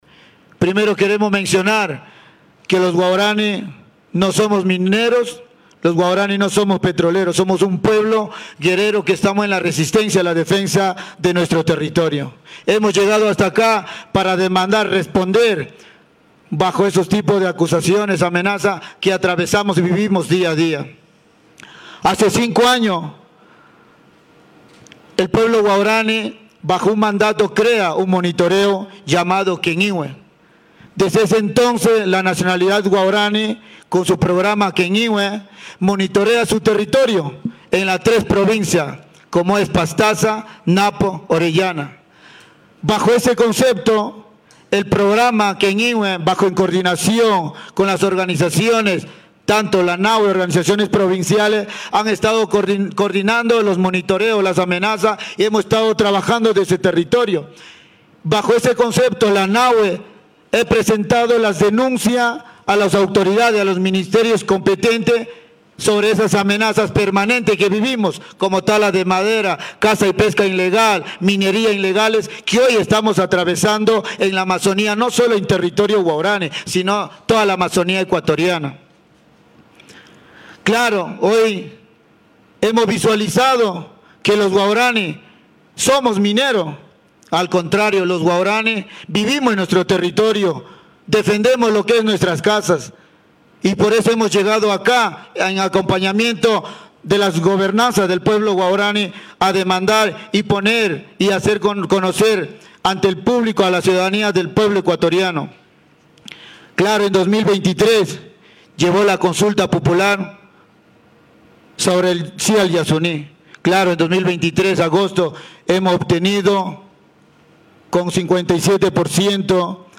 Indígenas de la Nacionalidad Waorani de las provincias Pastaza, Orellana y Napo se trasladaron el 8 de septiembre hasta Quito capital del Ecuador para mediante una rueda de prensa denunciar y rechazar, a varios sectores por haberles calificado de ser parte de los grupos que realizan actividades mineras en sus territorios.